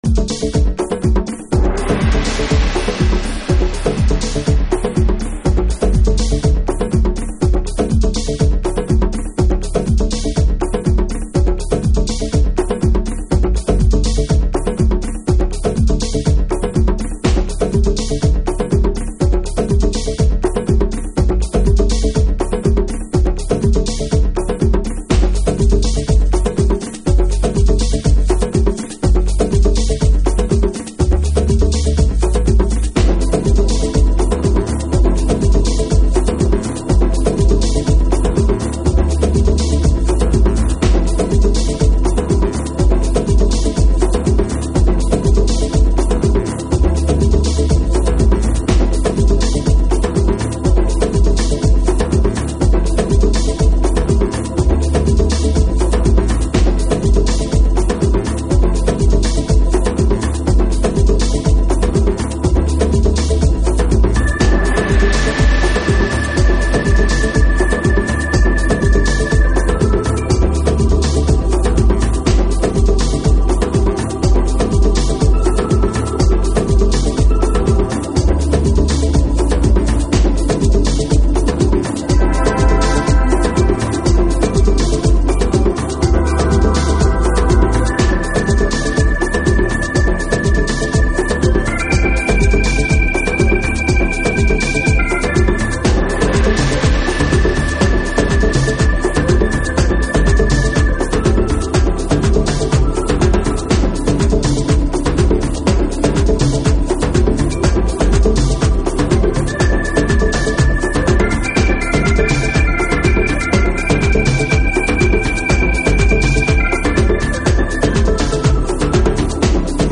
House / Techno
アフロ回帰派のスピリチュアルディープ、ずぶずぶまではいかない都会的なアレンジが煌びやかなグルーヴを作り出しています。